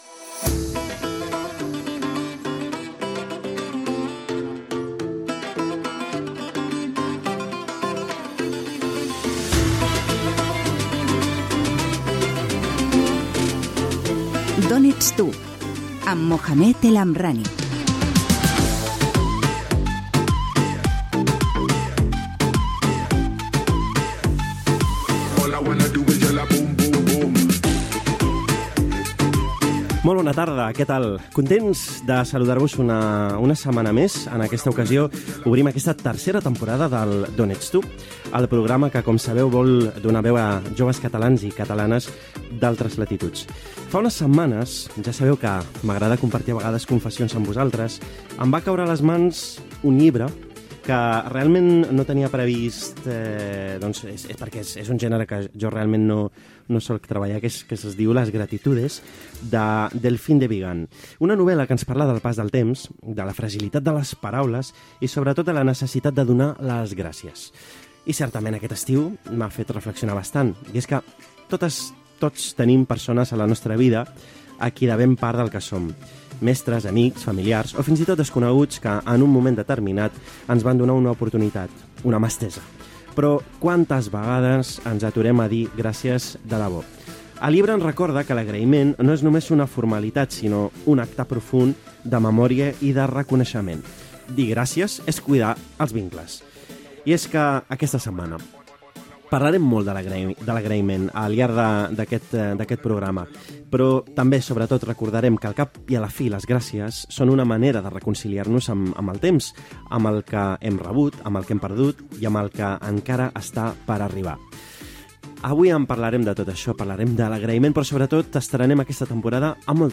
Careta del programa, presentació, objectiu del programa, comentari sobre el llibre "Las gratitudes" i el fet de saber donar les gràcies. "D'això tothom en parla" amb una entrevista al grup Sanguijuelas del Guadiana
Divulgació